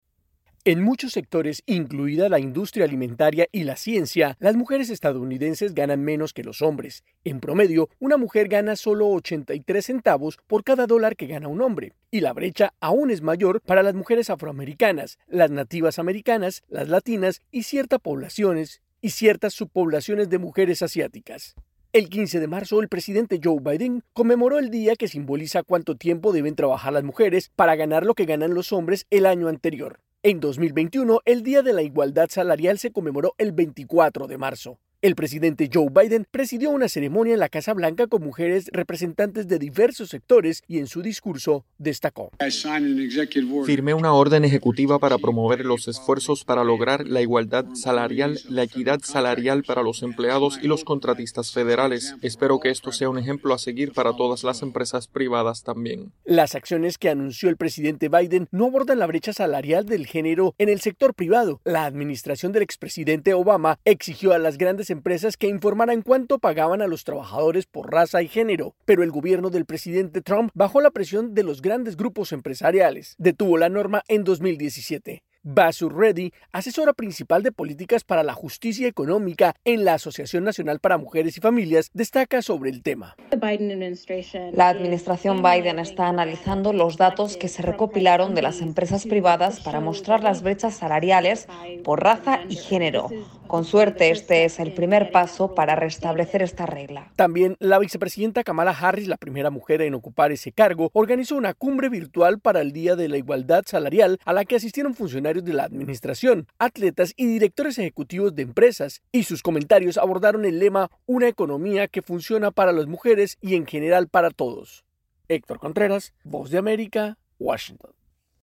AudioNoticias
desde la Voz de América en Washington, DC.